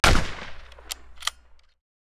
Firearms
Shot.wav